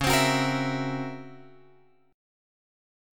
C# Minor Major 13th